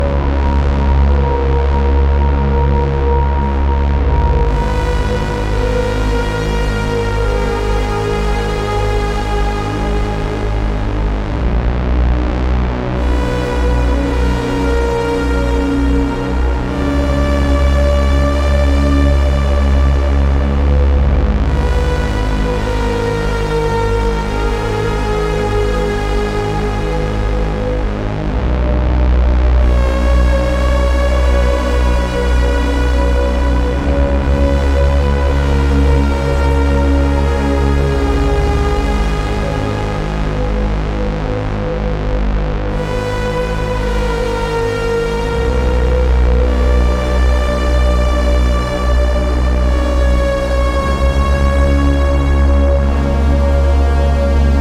electro
The EP is composed by five analogue bombs
electro discharge of haunting synth lines
which strike the perfect balance between depth and intensity